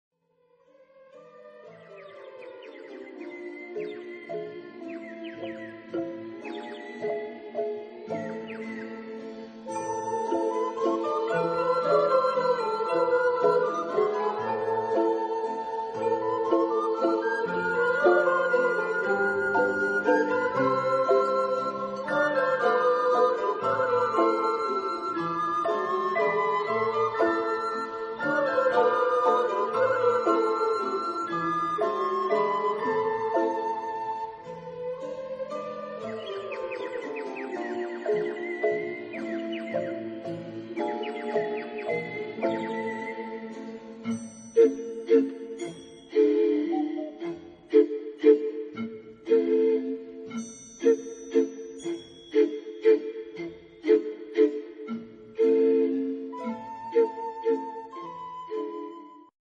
Genre-Style-Form: Ländler ; Imitation ; Popular ; Secular
Type of Choir: SSATTB  (6 mixed voices )
Instruments: Water bottle ; Bird whistle
Tonality: A minor